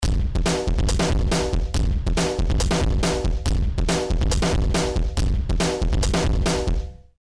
The following are mp3 samples that showcase different settings of Danstortion.
Drums
Drums_pos_neg.mp3